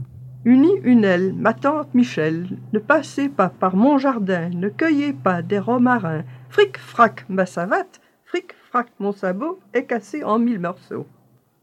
Genre : chant
Type : comptine, formulette
Interprète(s) : Anonyme (femme)
Lieu d'enregistrement : Inconnu (Mons, Bruxelles, Liège ou Haine-Saint-Paul)
Support : bande magnétique